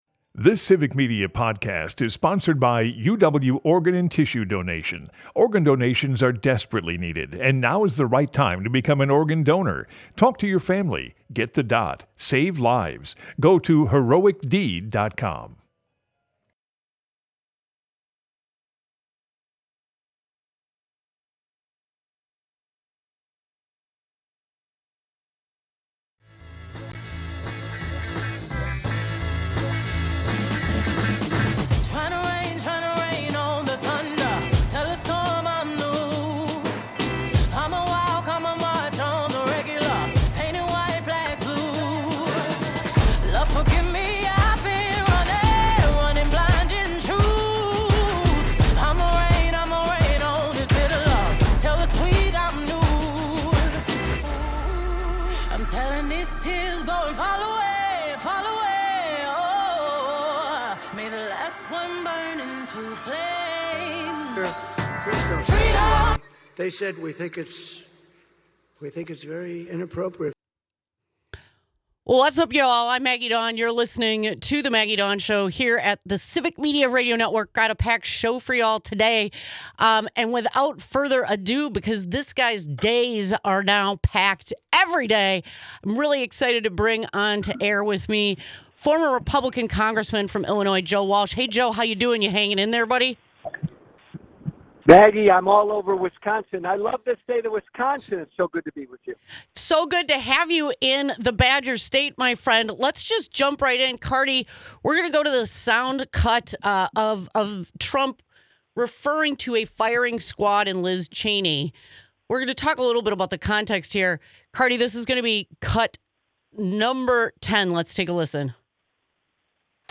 Broadcasts live, 2 - 4 p.m. across Wisconsin.